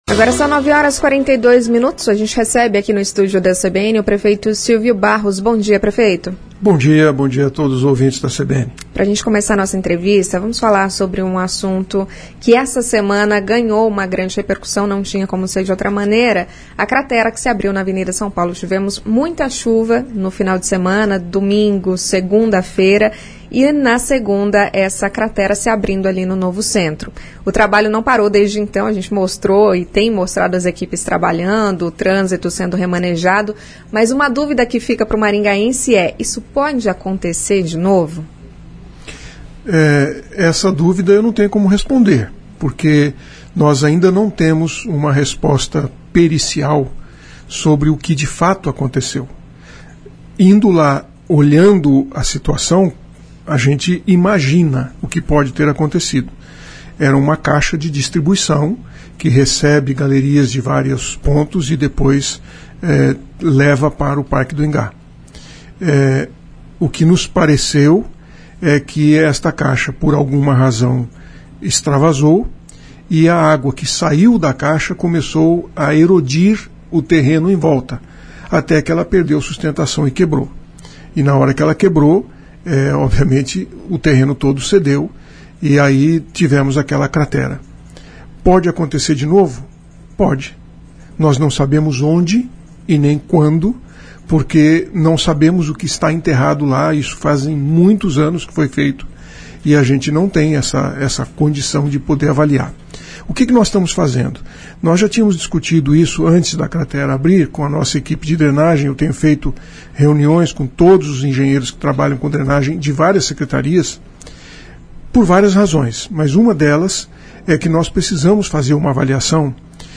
Em entrevista à CBN Maringá, o prefeito Silvio Barros falou sobre medidas que devem ser adotadas para melhorar a mobilidade urbana e citou, além das possíveis mudanças em vias da área central, o incentivo ao uso dos carros de aplicativo como medida para reduzir o trânsito em horários de pico, reservando áreas de estacionamento hoje destinadas carros particulares para serem usadas apenas por motoristas de app.